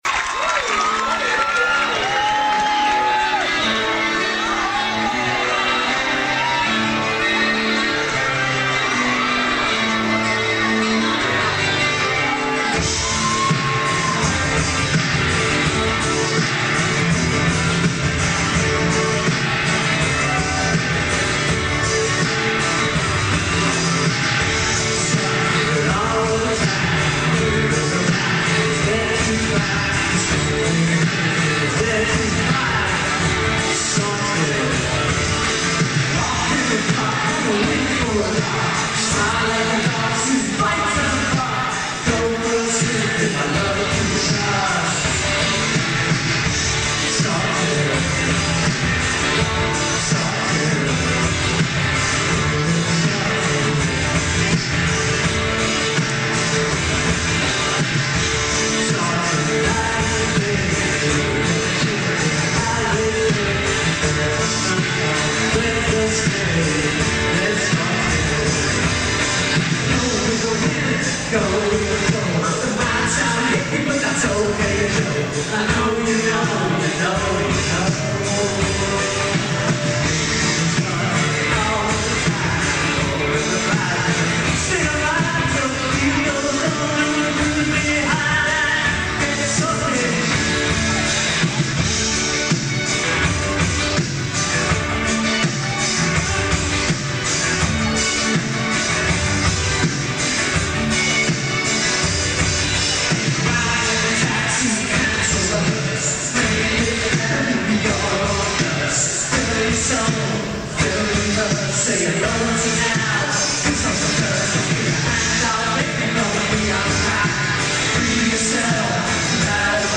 Warner Theater